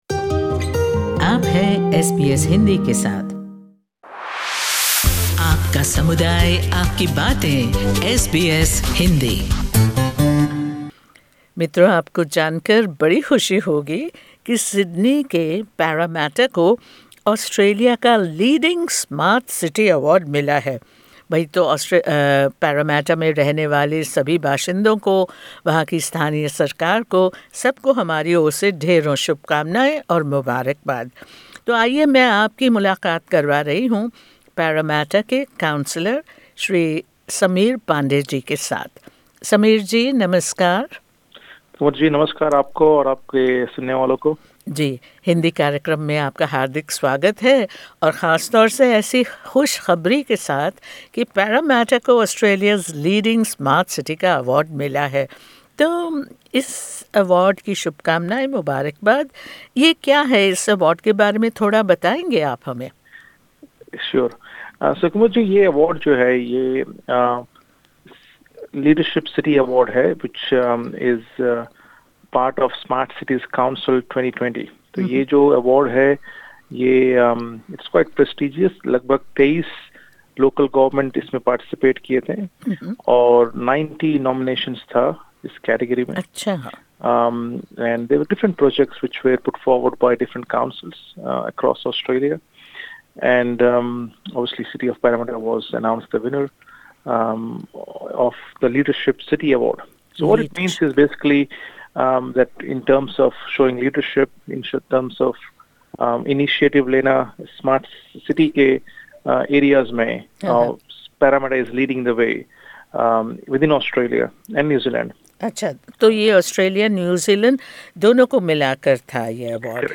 City of Parramatta's Councillor Sameer Pandey gives SBS Hindi the details.